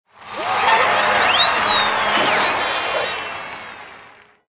KART_Applause_1.ogg